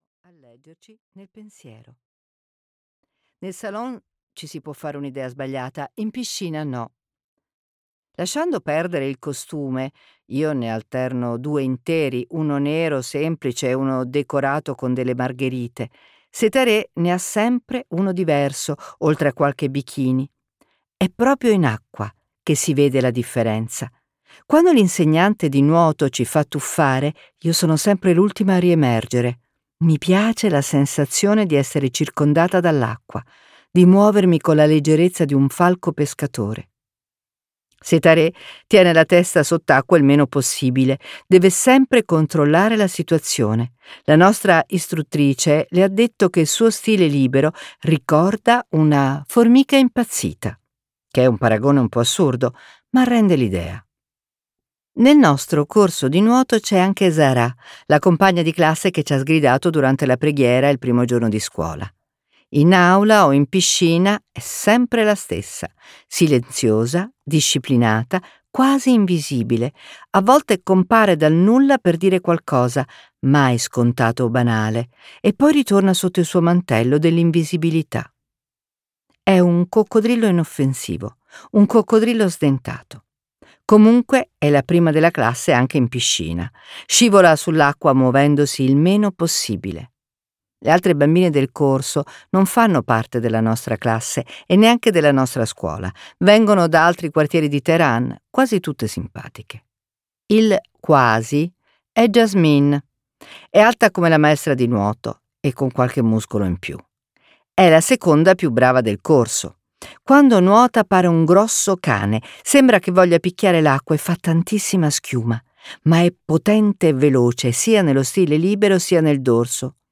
"La notte sopra Teheran" di Pegah Moshir Pour - Audiolibro digitale - AUDIOLIBRI LIQUIDI - Il Libraio